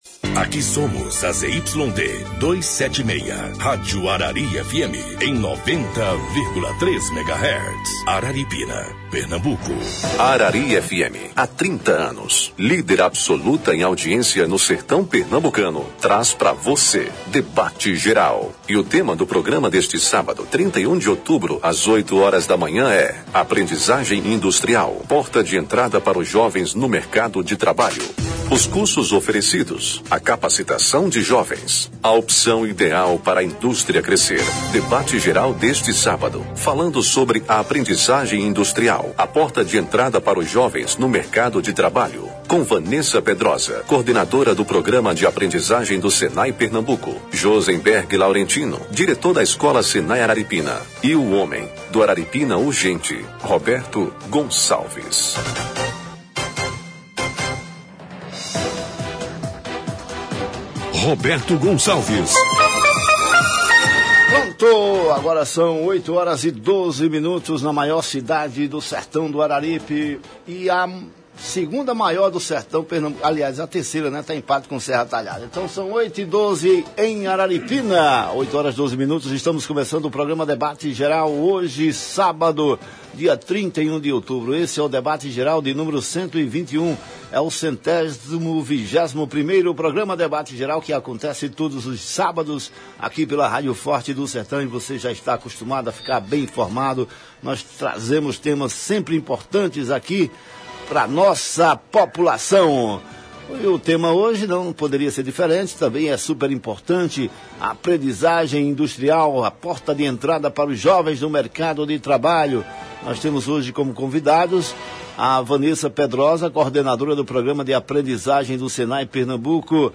O jornalismo da Rádio Arari FM 90,3 trouxe na manhã deste sábado 31 de outubro, o 121º programa Debate Geral.